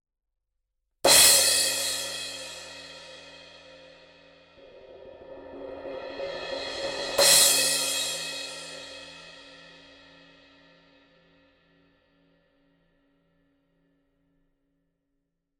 Теплый и богатый тембр. Очень быстрая реакция; при игре в край взрывается яркой вспышкой.